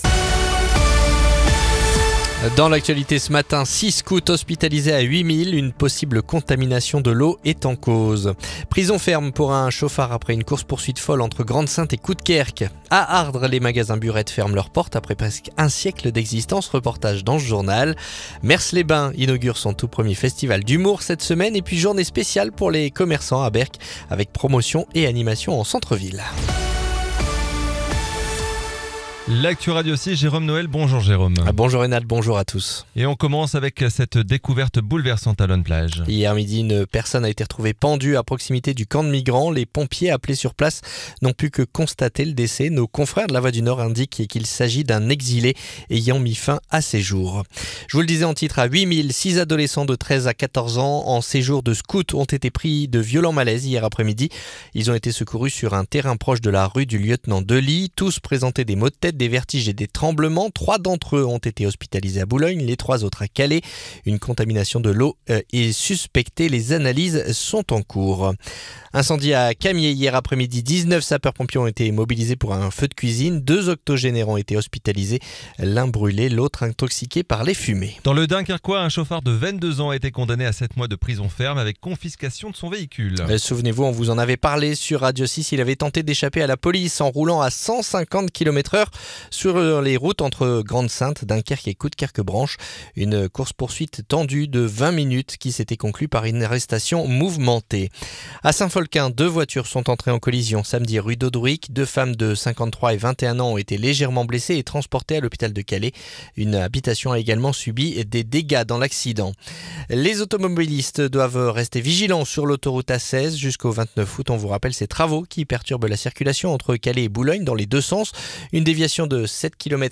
Le journal du lundi 18 août